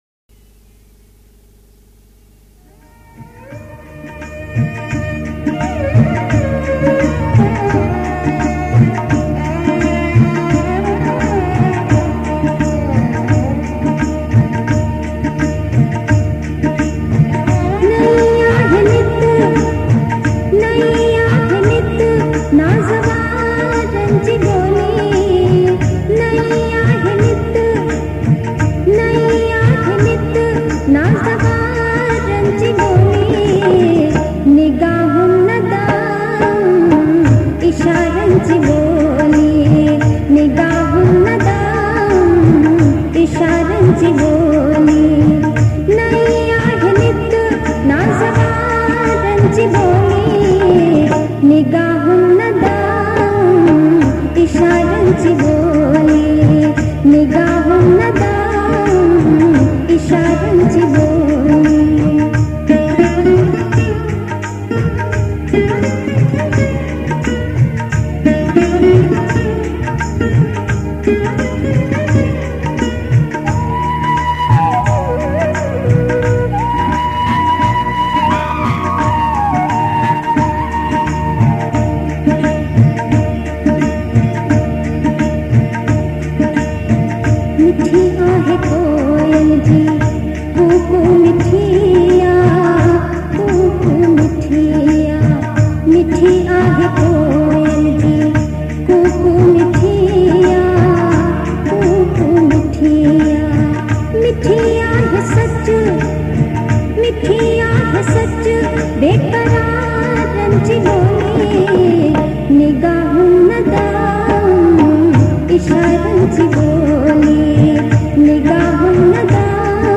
Sindhi Geet ain Kalam. Classical songs